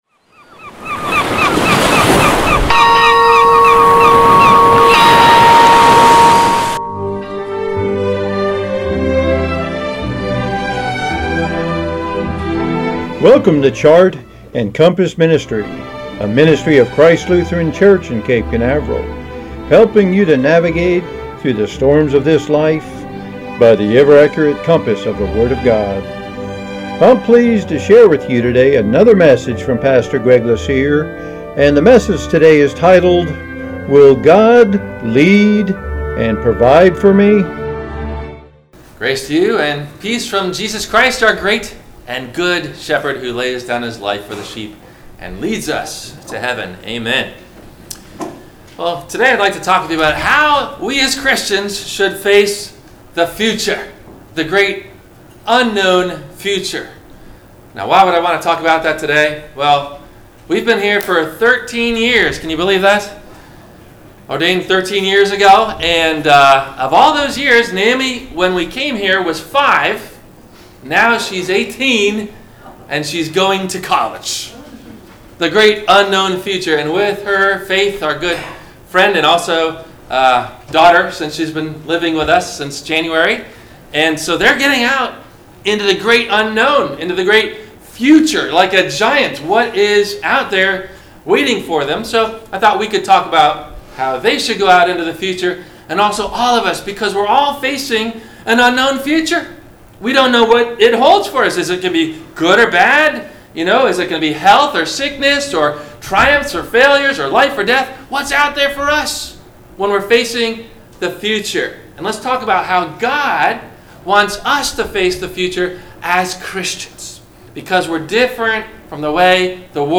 – WMIE Radio Sermon – September 17 2018 - Christ Lutheran Cape Canaveral
WMIE Radio – Christ Lutheran Church, Cape Canaveral on Mondays from 12:30 – 1:00